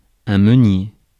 Ääntäminen
Synonyymit chevesne clitopile petite prune pinot meunier Ääntäminen France Tuntematon aksentti: IPA: /mø.nje/ Haettu sana löytyi näillä lähdekielillä: ranska Käännös Ääninäyte Substantiivit 1. miller US Suku: m .